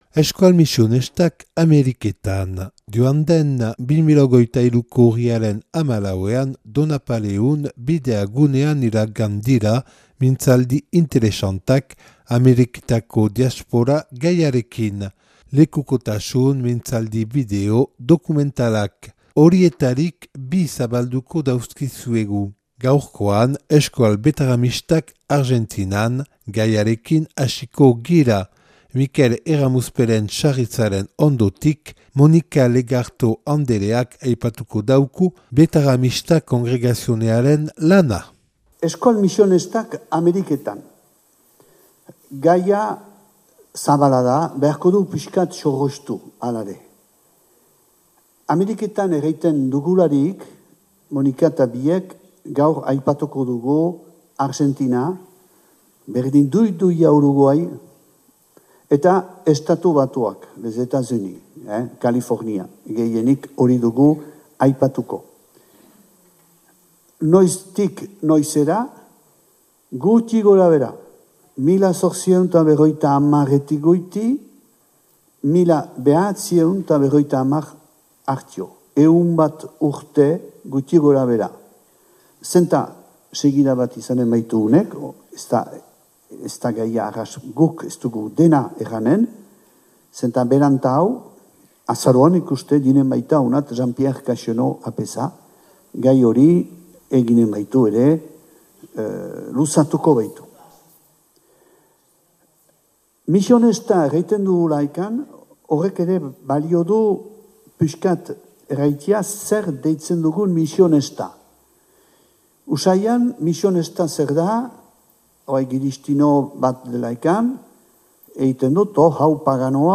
(2023. urriaren 14an grabatua Donapaleuko Bidea gunean).